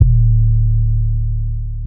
trunk shake 808.wav